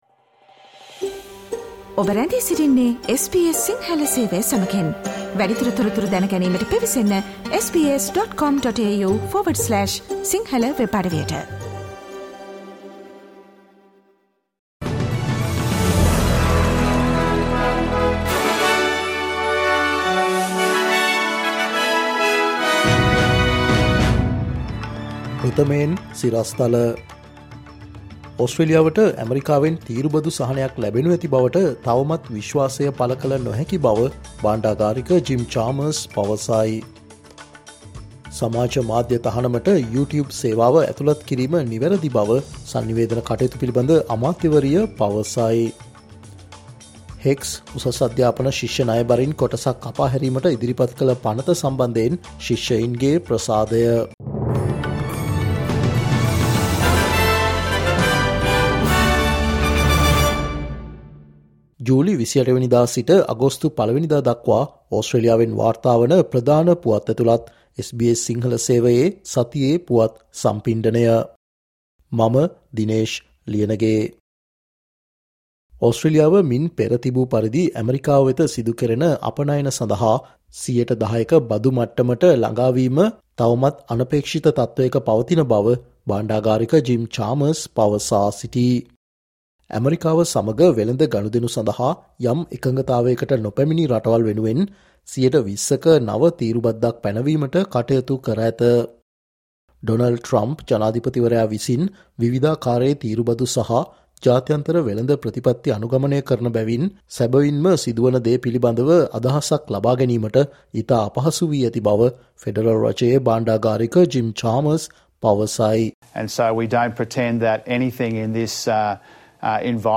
ජූලි 28 වනදා සිට අගෝස්තු 01 වනදා දක්වා වන මේ සතියේ ඕස්ට්‍රේලියාවෙන් වාර්තාවන පුවත් ඇතුලත් සතියේ පුවත් ප්‍රකාශයට සවන් දෙන්න